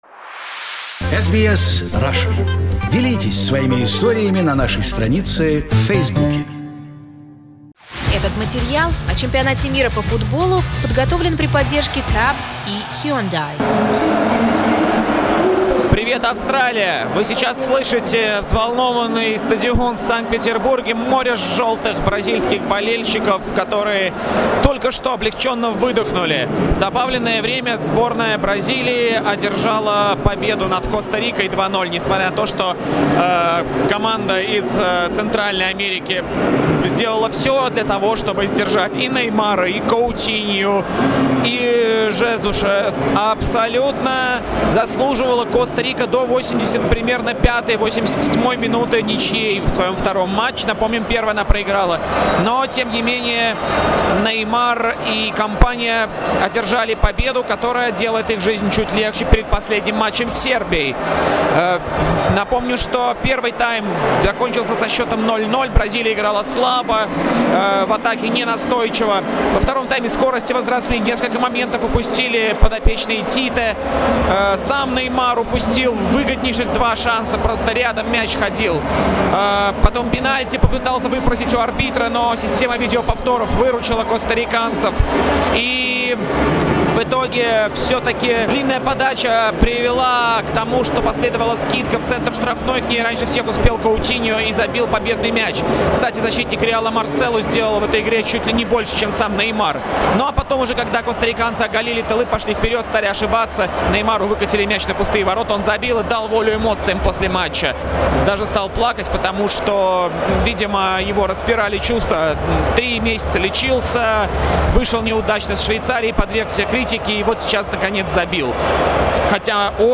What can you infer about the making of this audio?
spoke from the Saint Petersburg Stadium